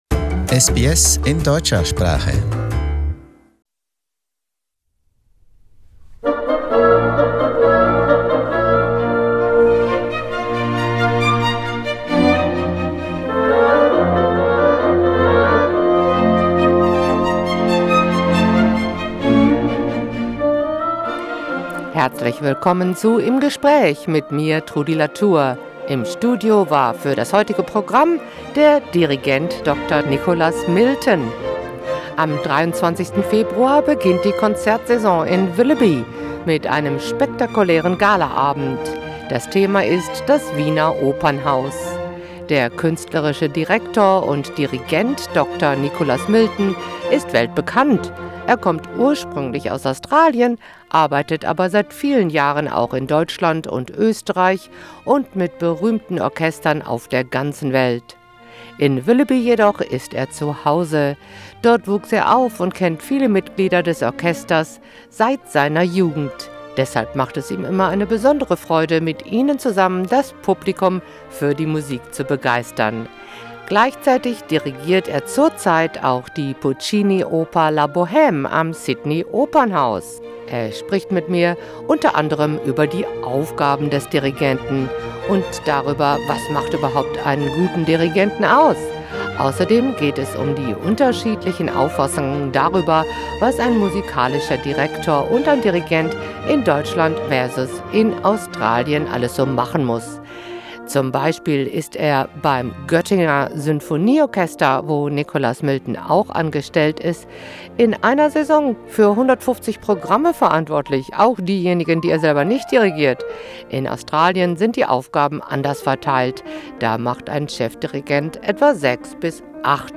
Im Gespräch: Was macht eigentlich der Dirigent?